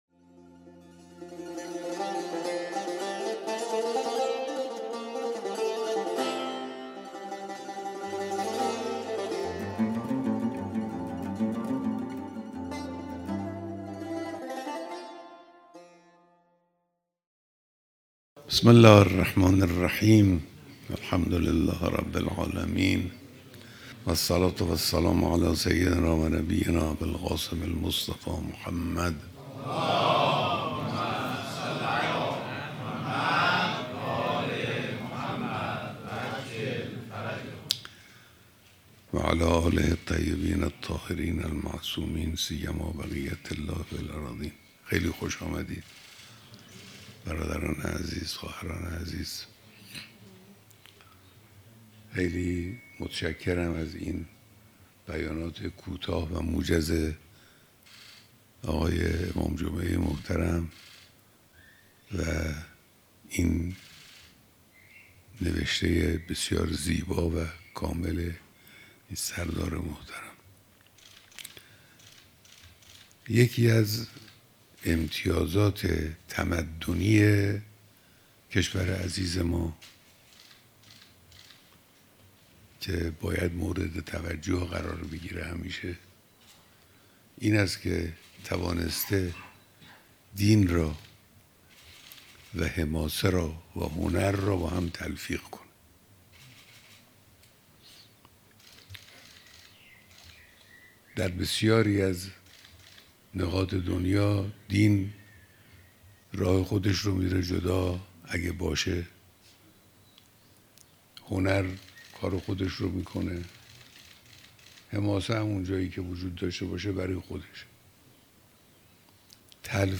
بیانات در دیدار دست‌اندرکاران برگزاری کنگره ۱۵ هزار شهید استان فارس